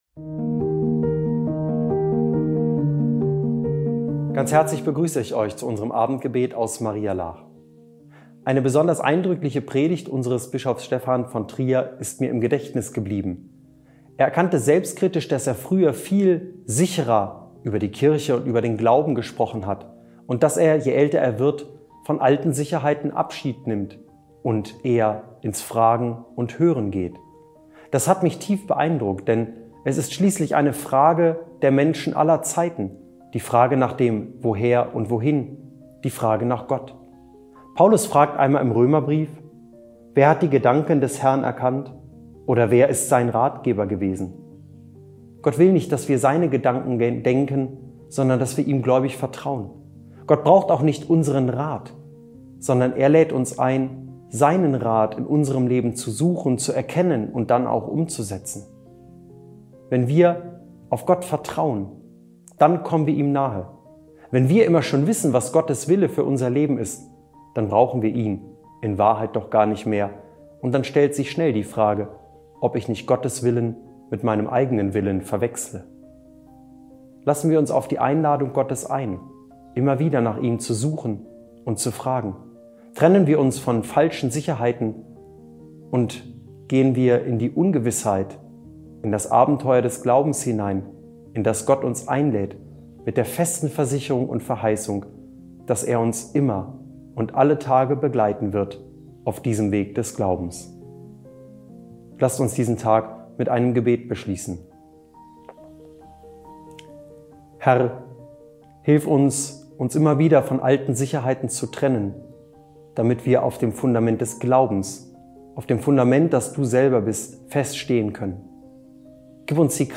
Abendgebet - 24. Mai 2025